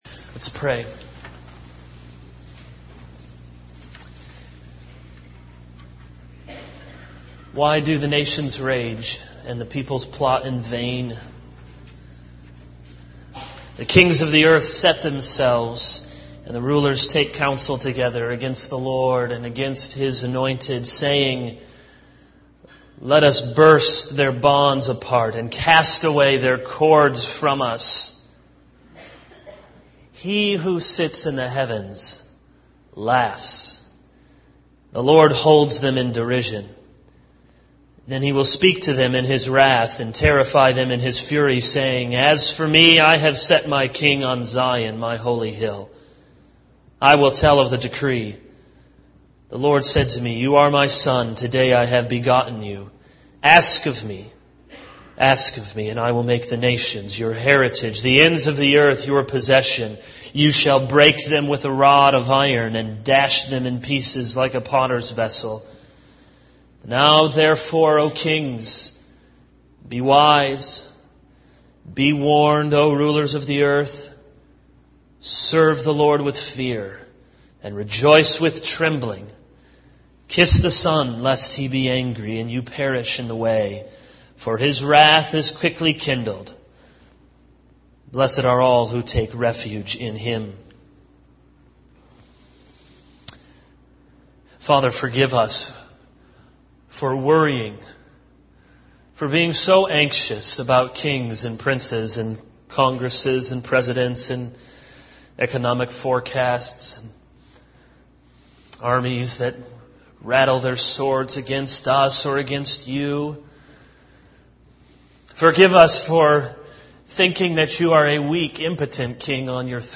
All Sermons Blood is Life 0:00 / Download Copied!